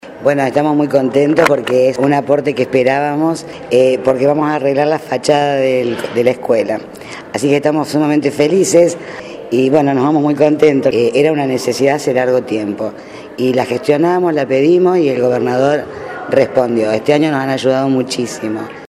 Palabras de autoridades escolares.